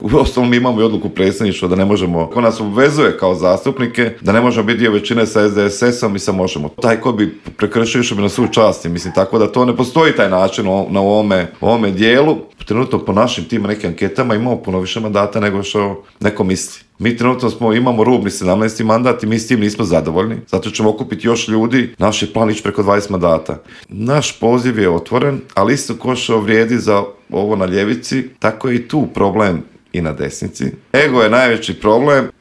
Samo su neka od pitanja na koje smo u Intervjuu Media servisa odgovore potražili od saborskog zastupnika iz Domovinskog pokreta Marija Radića koji je najavio veliki politički skup stranke 23. ožujka u Zagrebu.